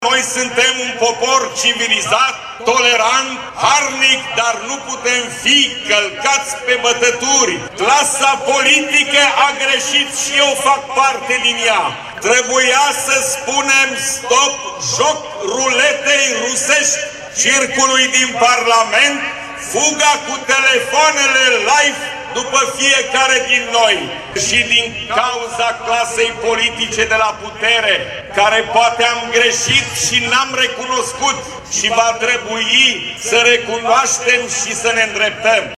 Președintele Consiliului Județean Suceava GHEORGHE FLUTUR a făcut apel – în cadrul mitingului de ieri organizat pe esplanada centrală – la păstrarea parcursului european al României, alături de Uniunea Europeană și NATO.